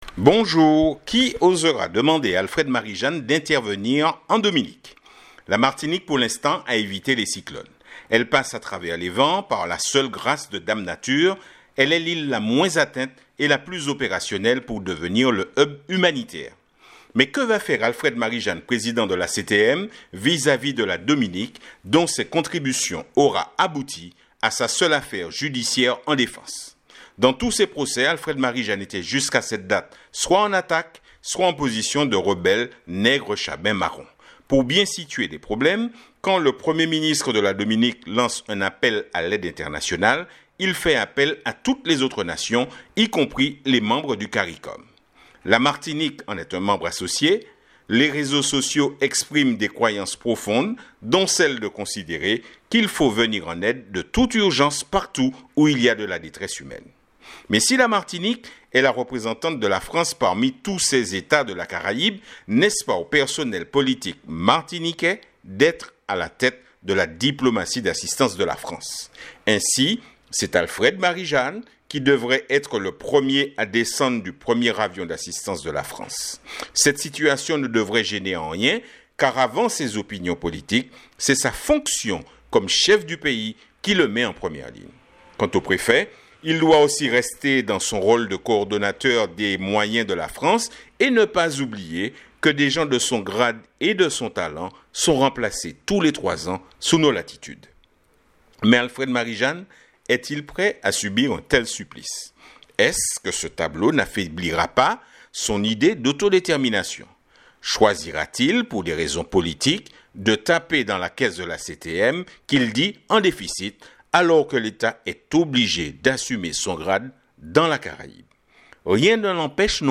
Pour écouter l'éditorial cliquez sur la triangle blanc dans le rectangle noir .